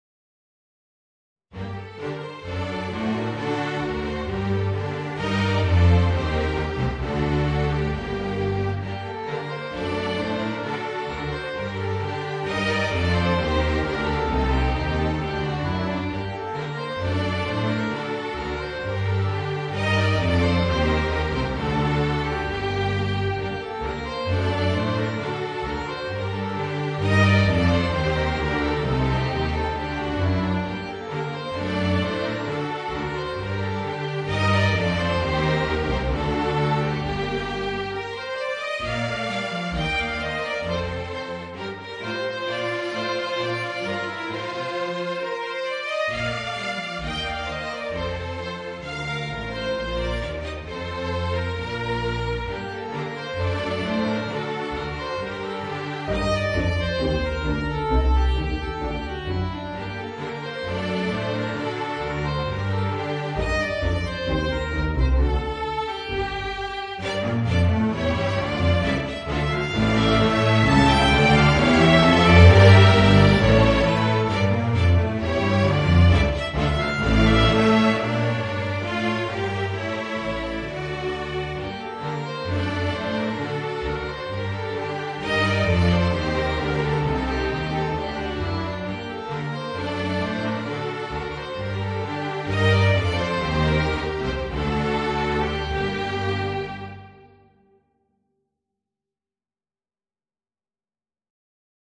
Voicing: Viola and String Orchestra